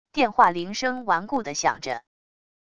电话铃声顽固的响着wav音频